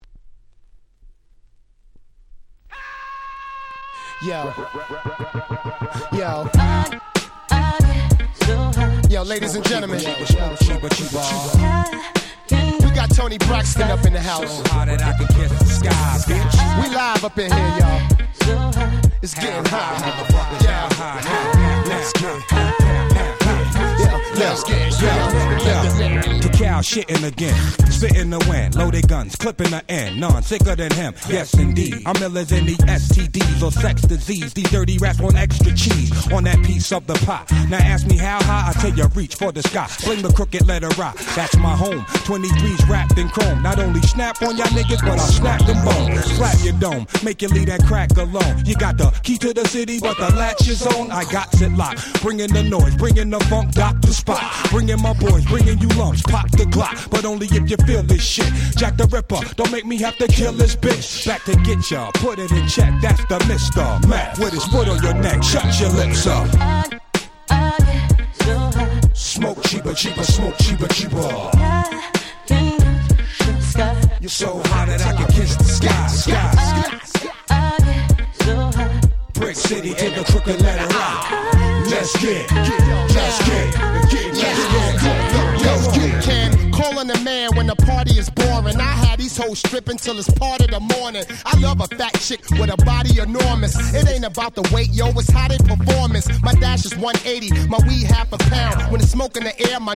01' Smash Hit Hip Hop !!
Boom Bap